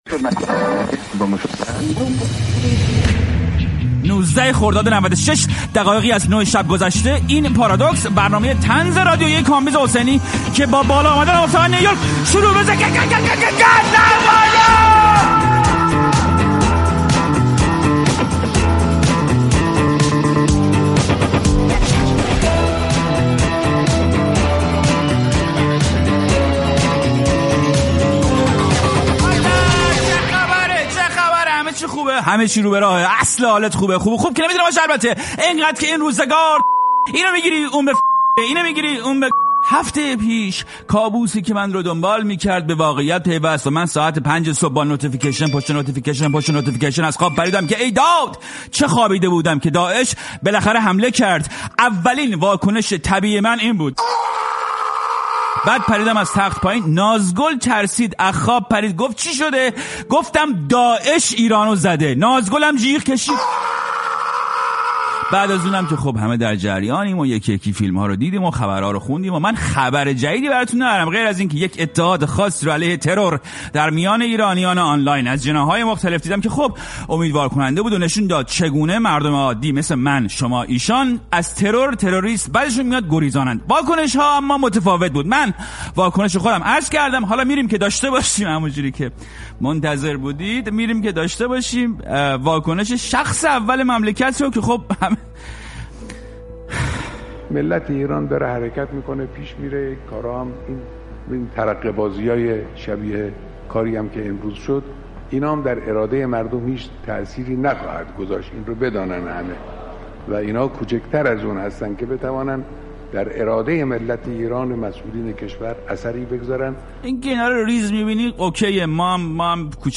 پارادوکس با کامبیز حسینی گفت و گو